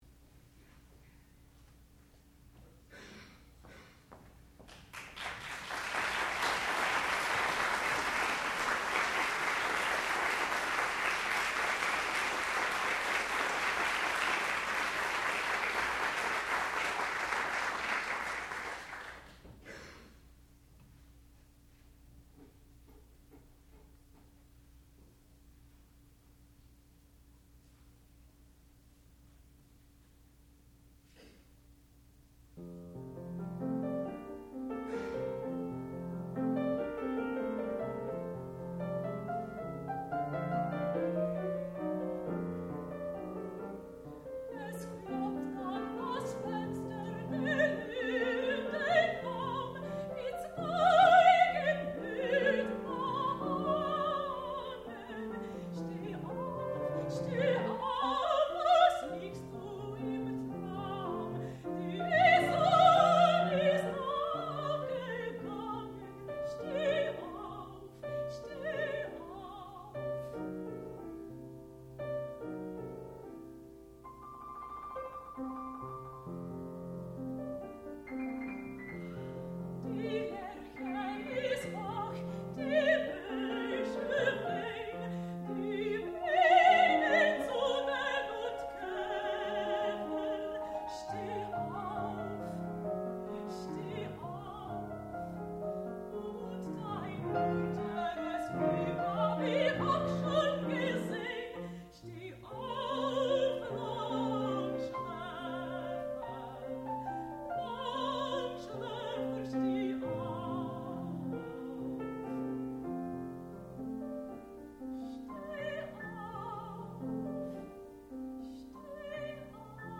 sound recording-musical
classical music
mezzo-soprano
piano